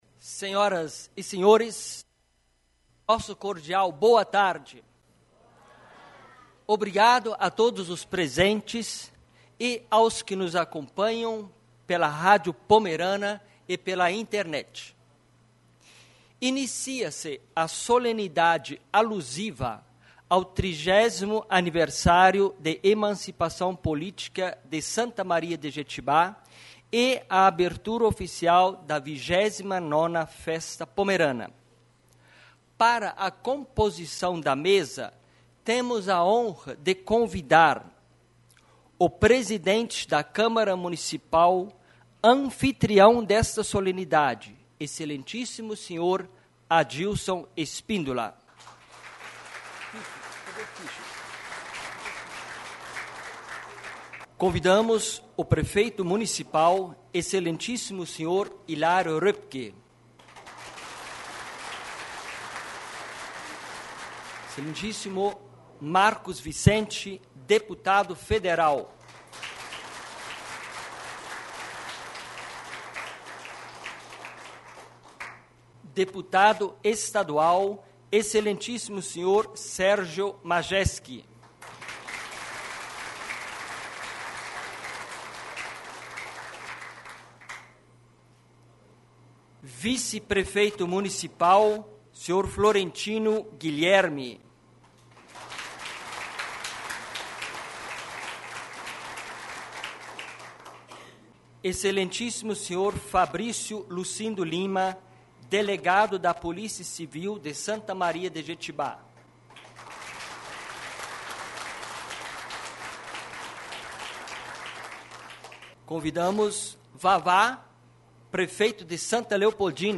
14ª Reunião Solene da 2ª Sessão Legislativa da 8ª Legislatura 03 de Maio de 2018